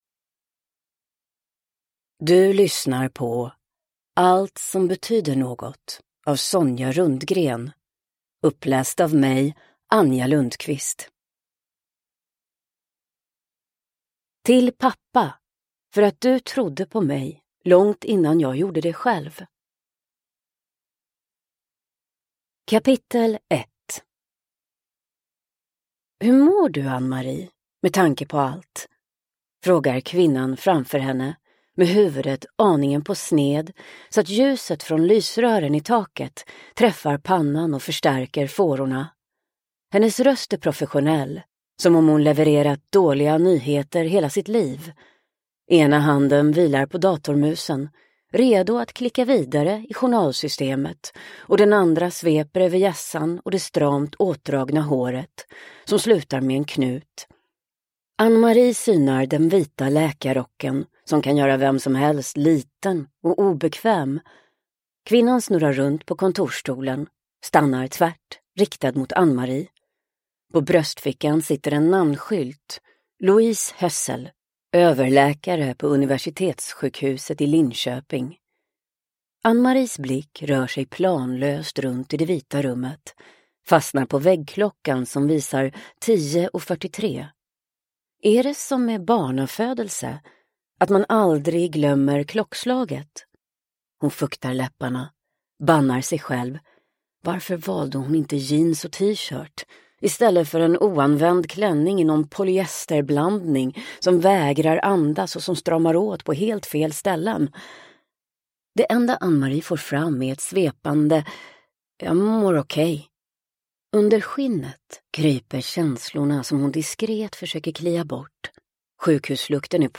Allt som betyder något / Ljudbok